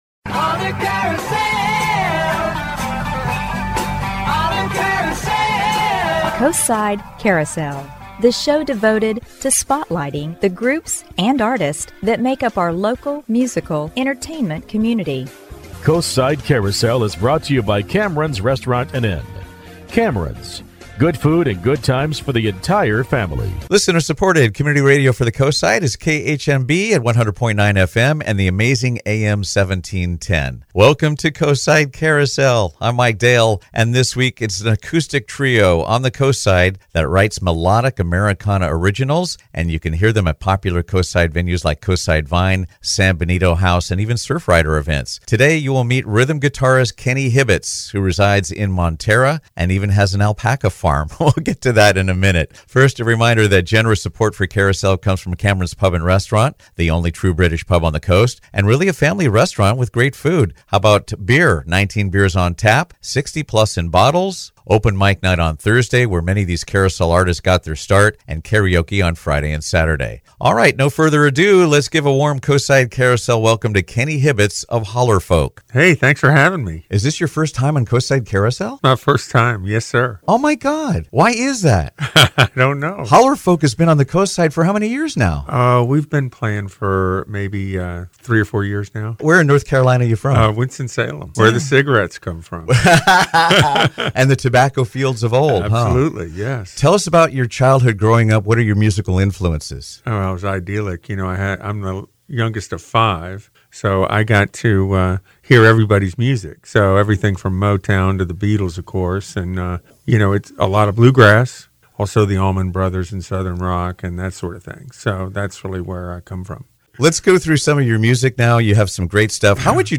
Coastside Carousel is the last segment of the one-hour show called Radio Magazine.